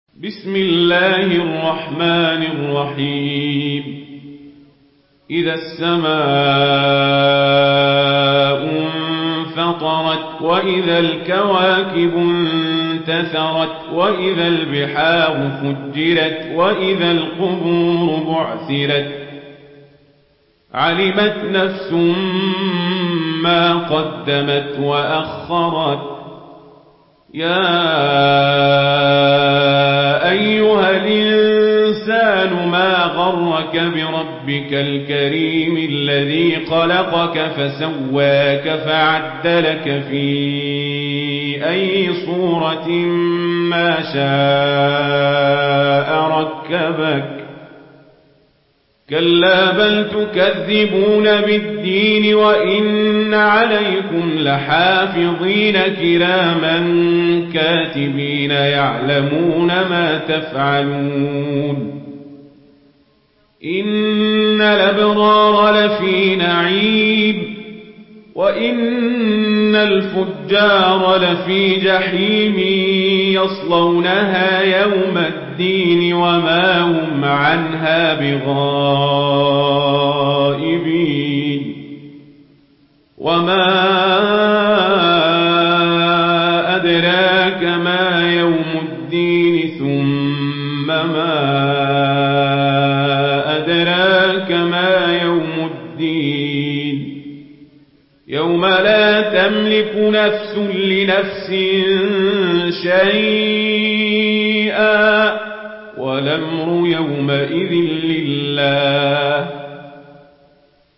Une récitation touchante et belle des versets coraniques par la narration Warsh An Nafi.
Murattal Warsh An Nafi